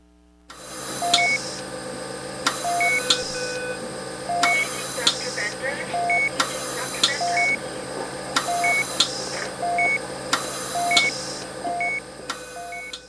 Hospital01.wav